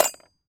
metal_small_movement_08.wav